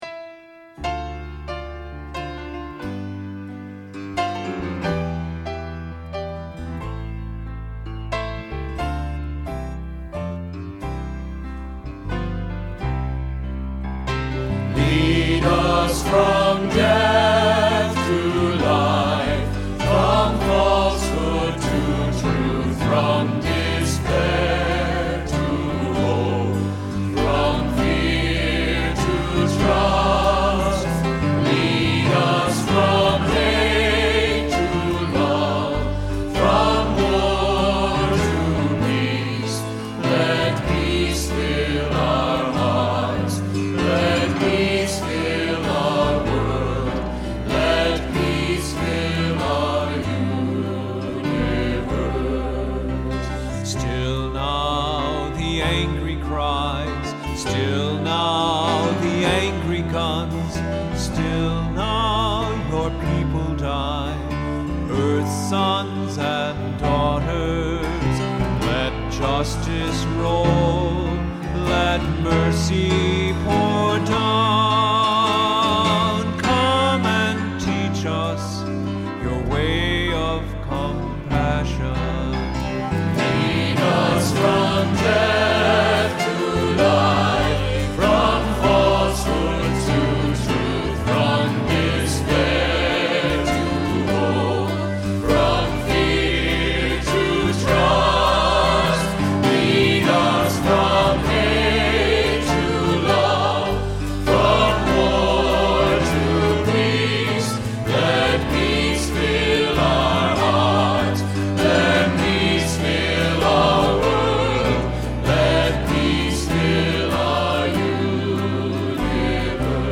Accompaniment:      Keyboard
Music Category:      Christian
Unison or 2 equal or mixed voices.Ê